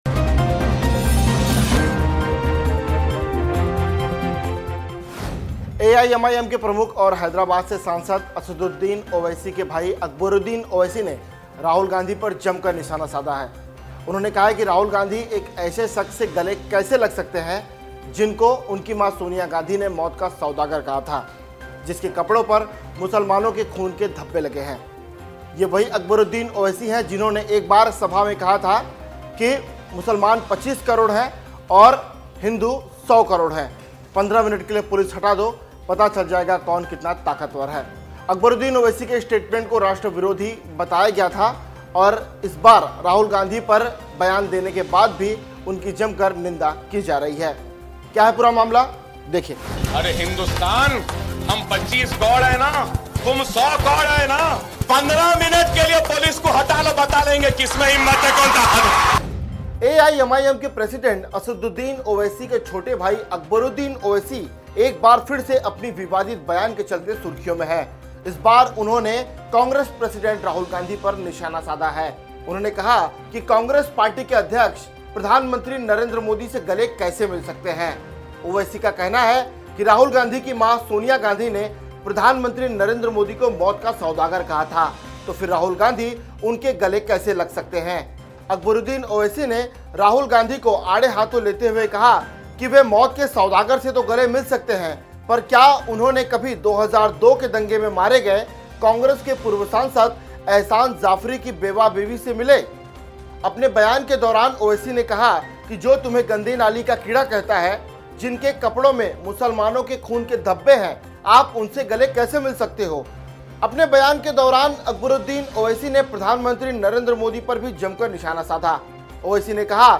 न्यूज़ रिपोर्ट - News Report Hindi / अकबरुद्दीन ओवैसी, राहुल गांधी के सॉफ्ट हिंदुत्व से हुए नाराज ?